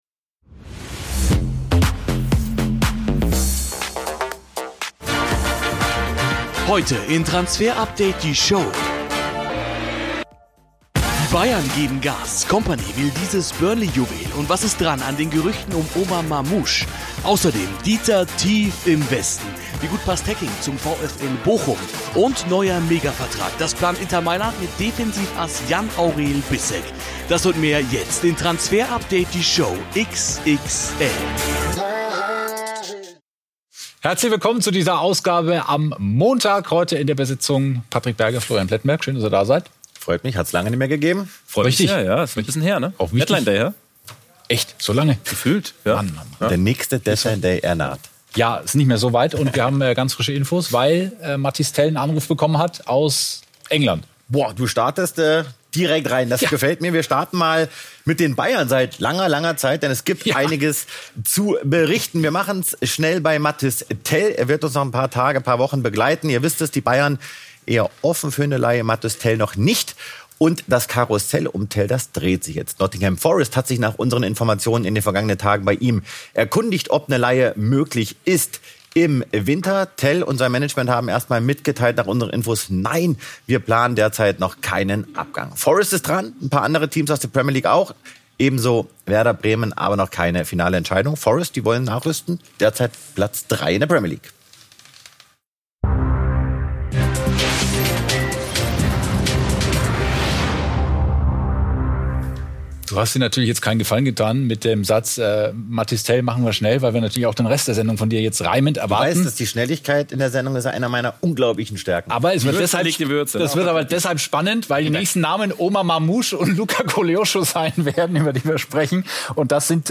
Transfer Update - die Show rund um den Transfermarkt. Exklusive Infos, Analysen und Gerüchte - hier werdet Ihr von unseren Sky-Reportern auf den aktuellsten Stand gebracht. Die komplette Sendung zum Nachhören gibt es hier als Podcast.